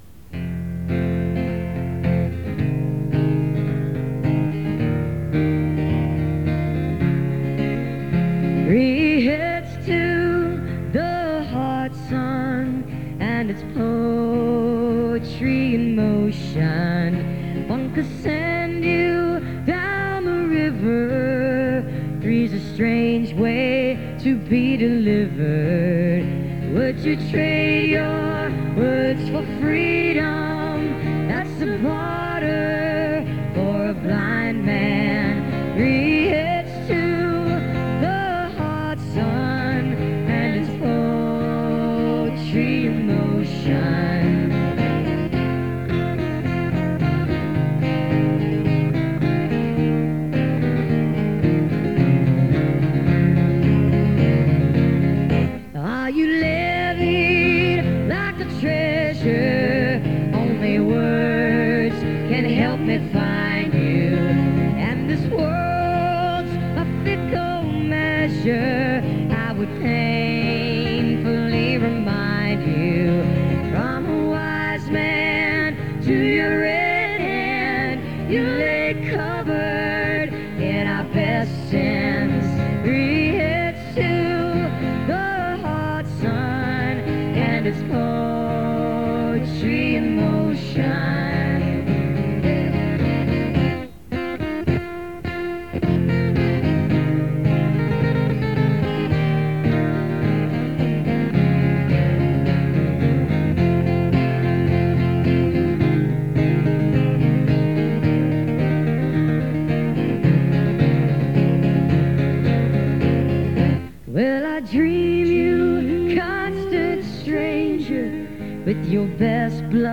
(acoustic duo)
live in studio